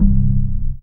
ORGAN-08.wav